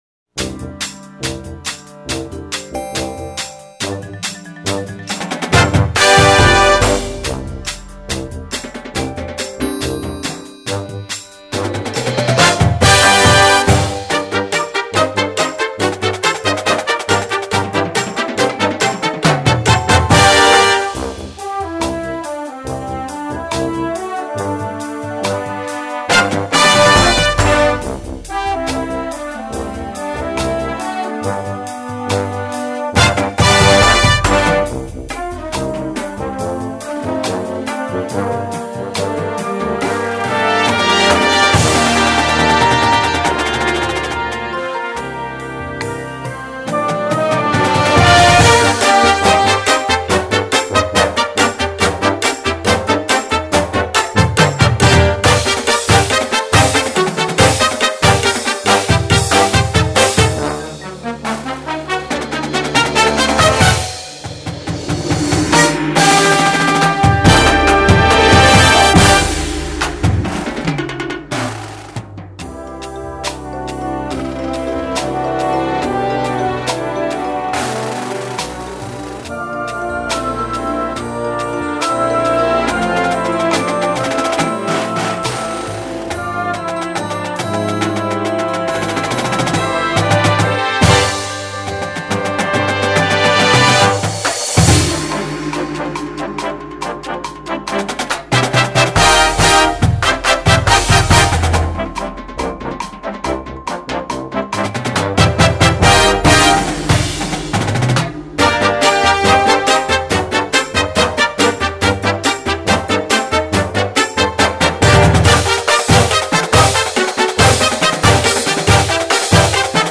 Répertoire pour Harmonie/fanfare - Marching Band